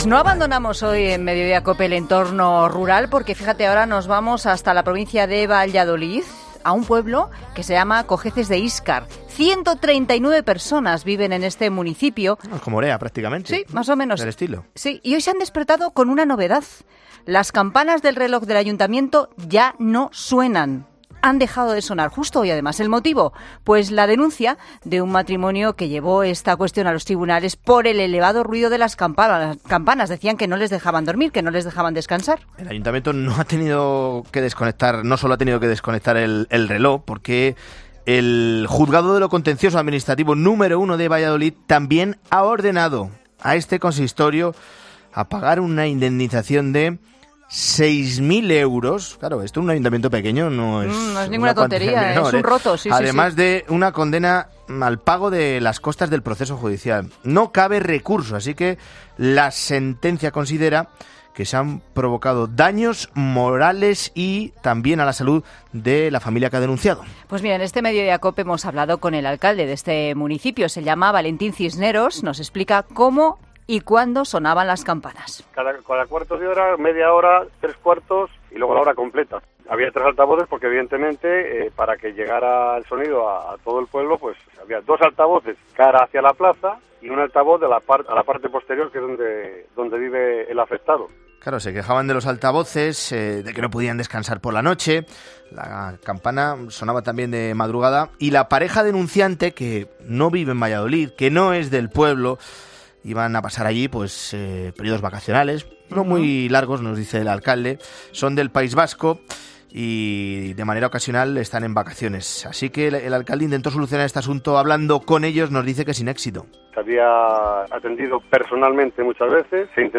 Entrevista al alcalde de Cogeces de Íscar, Valentín Cisneros